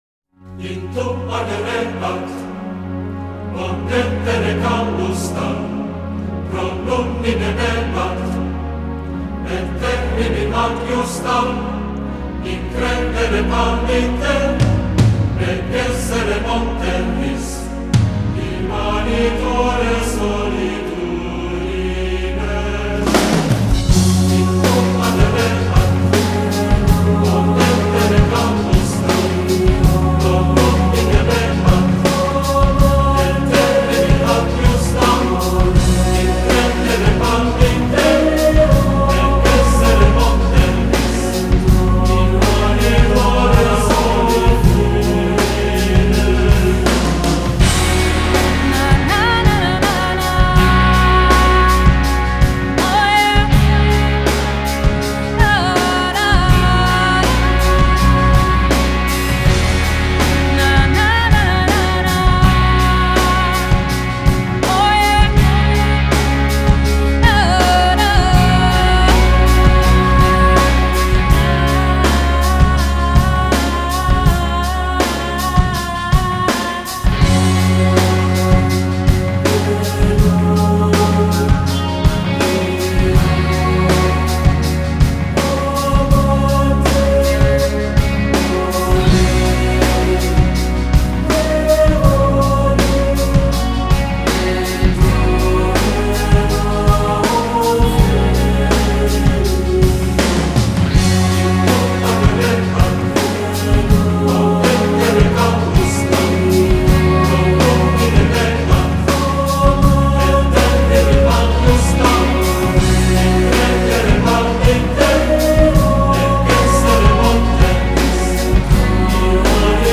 这里的曲子音质都还不错